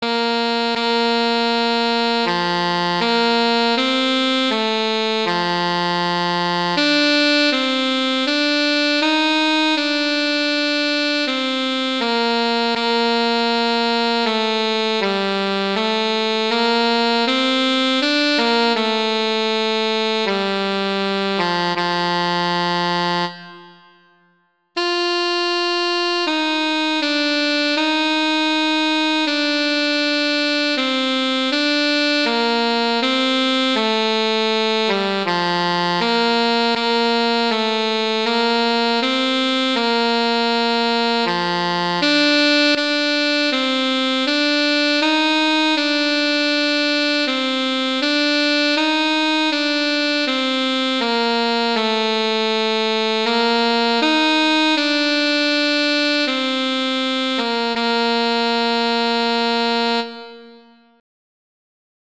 alto saxophone solo